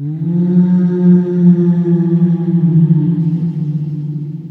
ghost.ogg